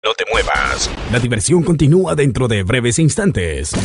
Efectos de sonido